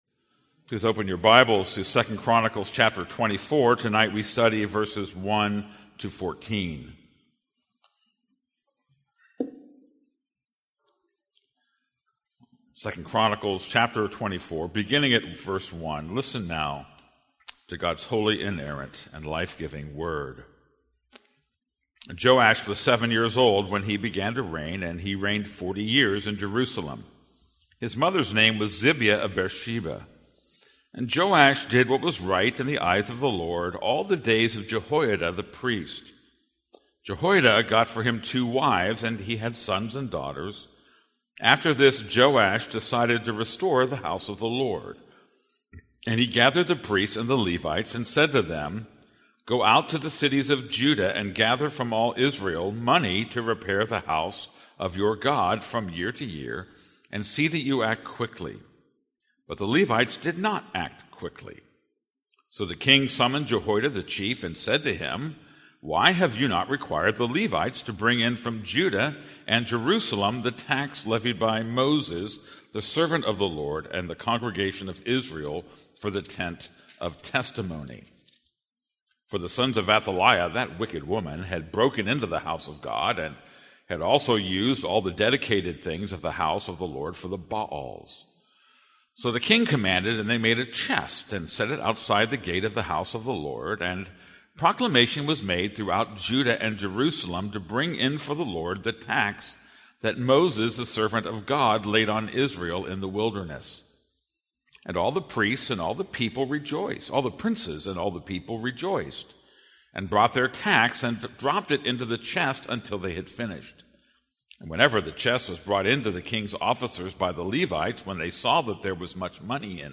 This is a sermon on 2 Chronicles 24:1-14.